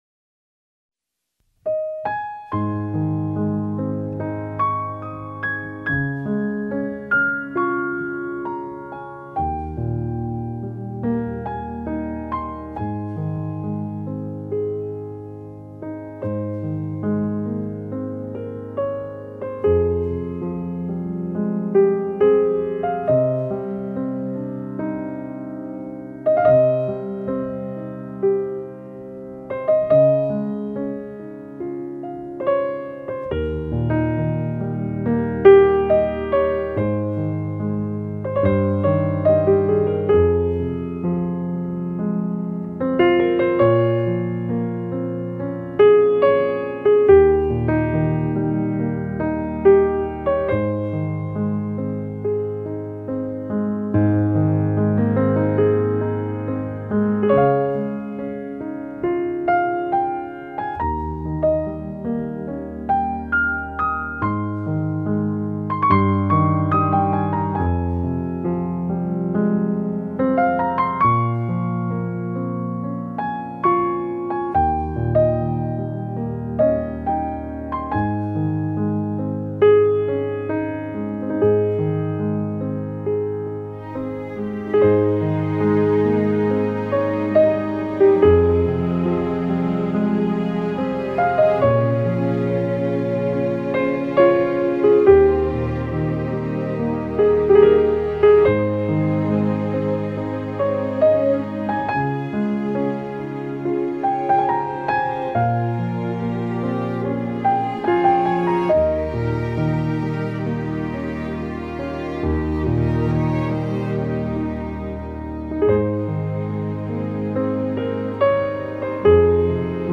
鋼琴
絃樂
淡淡哀愁的鋼琴音色
部分曲子加入弦樂、吉他、手風琴等樂器，呈現更豐富的音樂氛圍。
用最溫柔、平和的曲調表現出來。